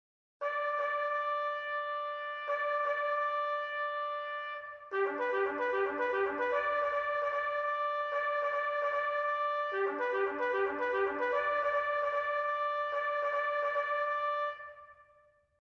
Traditional Bugle Call : To Arms
This is the traditional bugle call known as "To Arms", and it is used by military forces to order troops to assemble with their weapons.